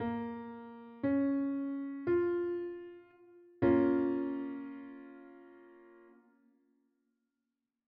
The audio examples in this course will always play triads both melodically and harmonically.
A minor triad sounds very similar to a major triad, which makes sense as there is only one note different.
Bb-Minor-Triad-S1.wav